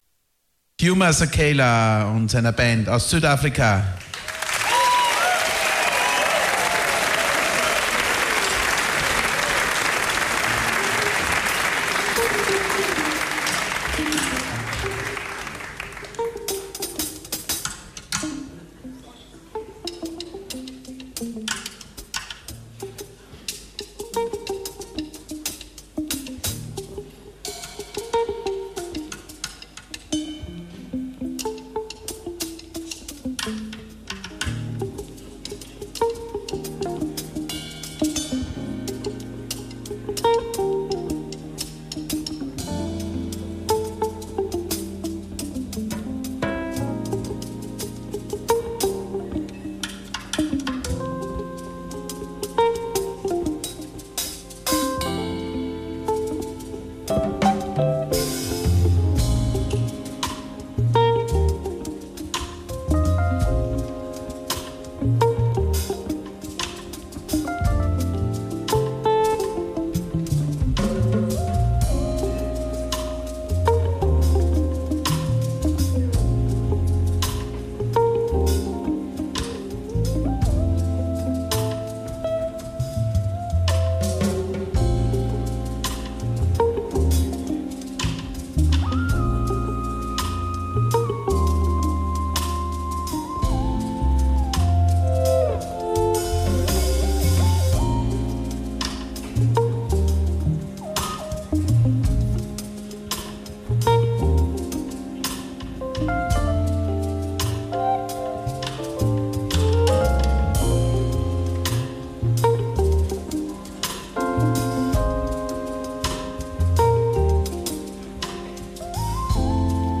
his delicious roux of Afrobeat Jazz